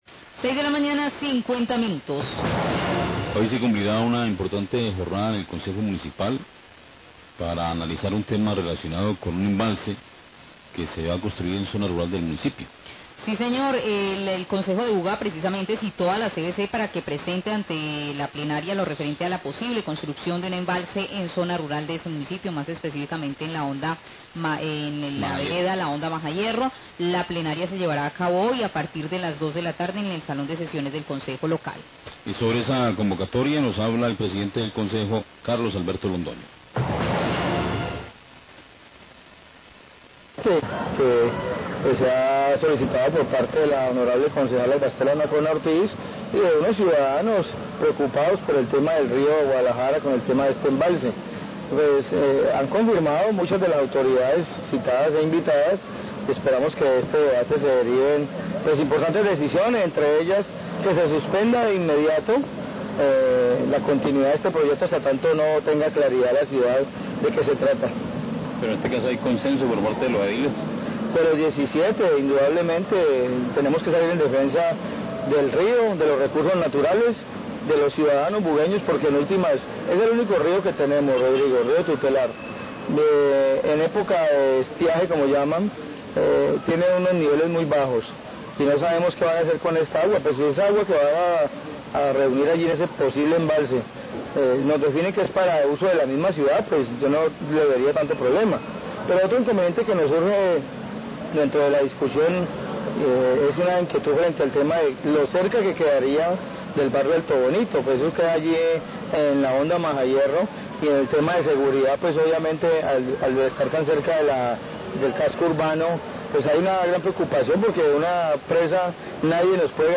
Hoy se cumplirá una importante jornada en el Concejo Municipal para analizar el tema de la construcción de un embalse en zona rural de Buga. El Concejo Local citó a la CVC para que presente ante la plenaria lo referente a este proyecto. Presidente del concejo se refiere a esta sesión que se llevará a cabo hoy.